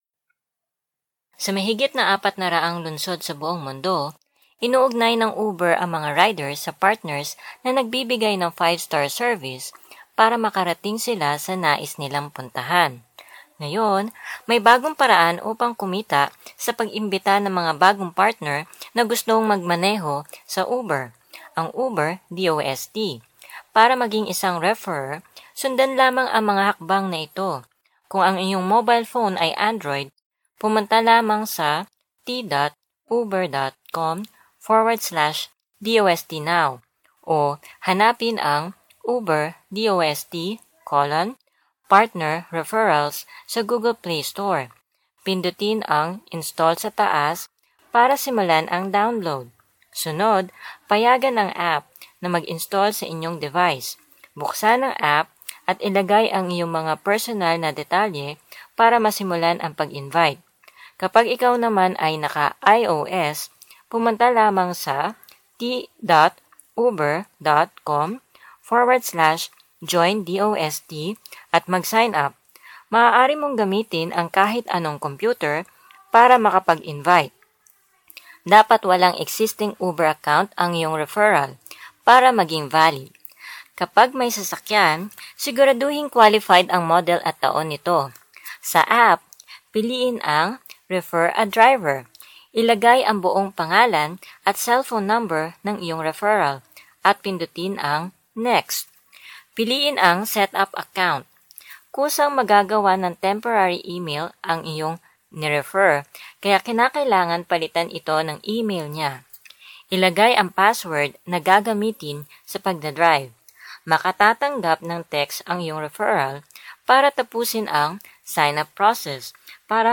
TAG KC EL 01 eLearning/Training Female Tagalog